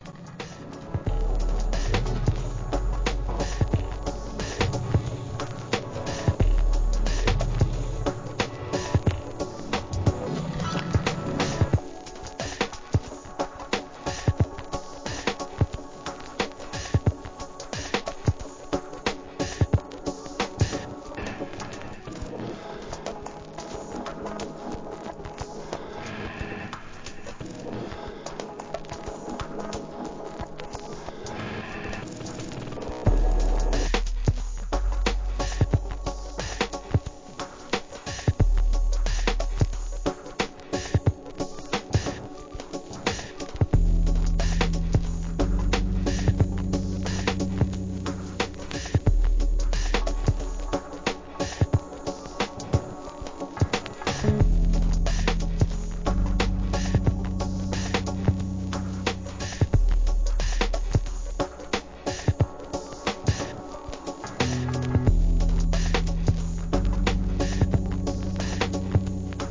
DOWNTEMPO, ブレイクビーツ